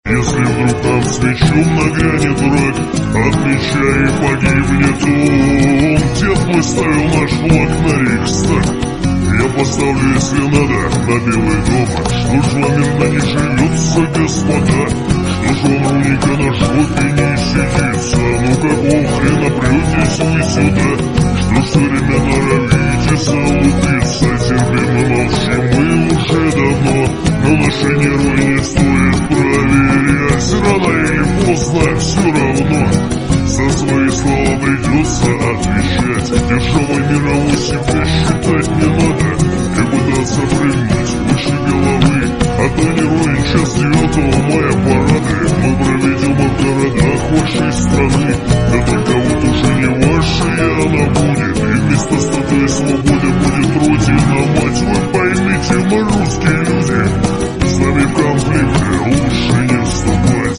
мужской голос
спокойные
угрожающие